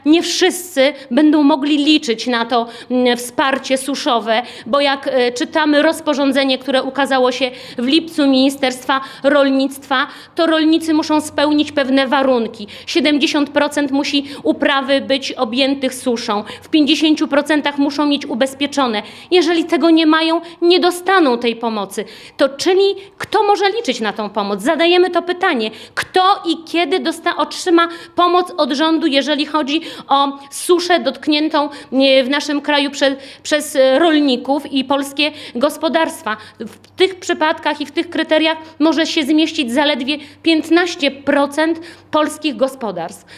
Apel do rządu i premiera Mateusza Morawieckiego wystosowała w poniedziałek (06.08.18) podczas konferencji prasowej w swoim biurze poselskim Bożena Kamińska, posłanka Platformy Obywatelskiej.